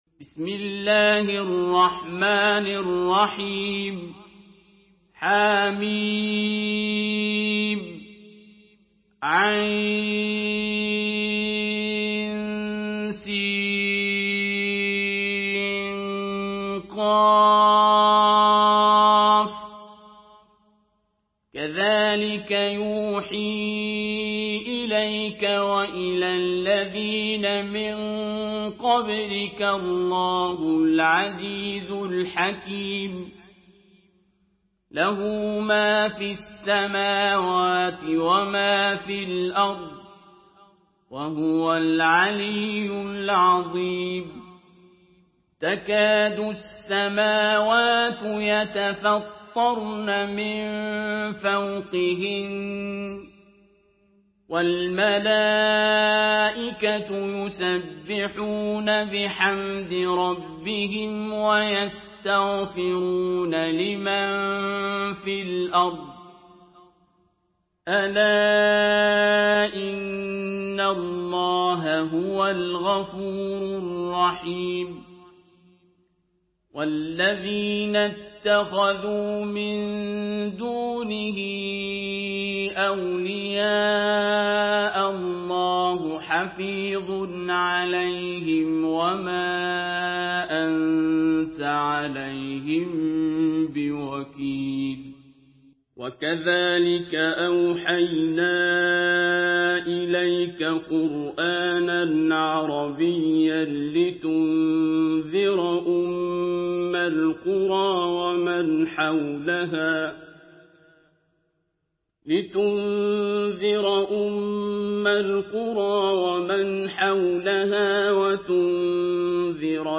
Surah Ash-Shura MP3 in the Voice of Abdul Basit Abd Alsamad in Hafs Narration
Murattal Hafs An Asim